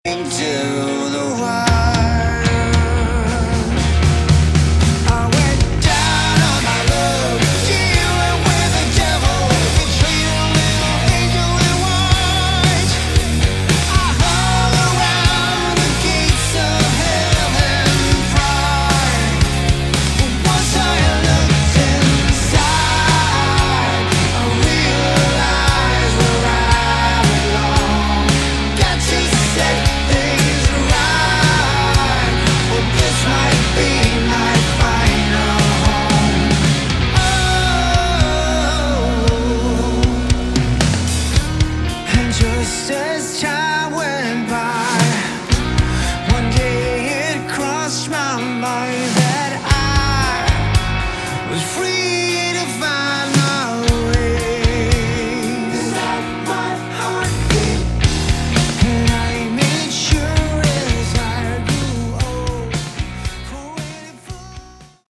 Category: Melodic Hard Rock
guitars
bass, vocals
drums